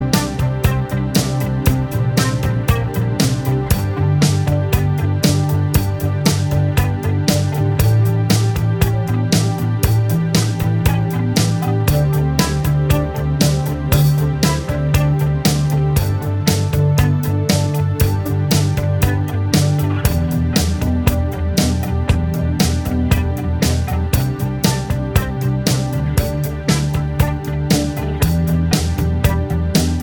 Two Semitones Down Pop (1980s) 3:56 Buy £1.50